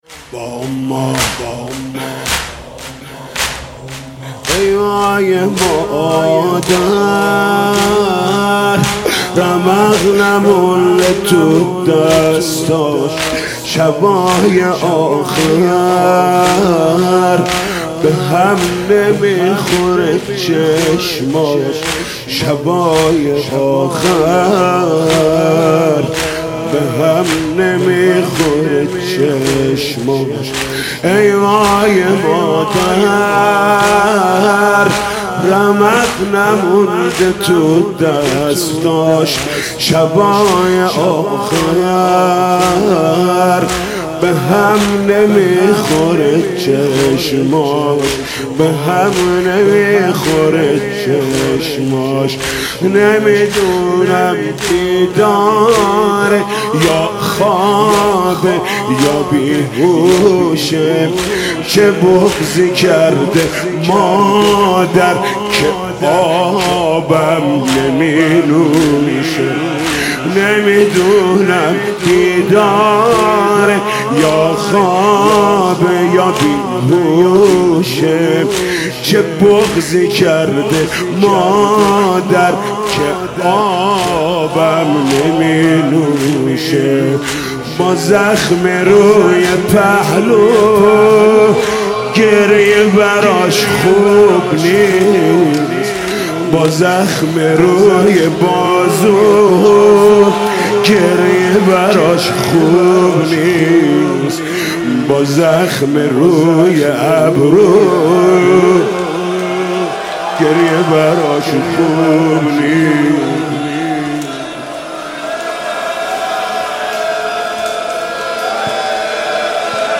مداحی فاطمیه 1396
(زمینه)